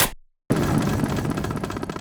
WinterTales - Button.ogg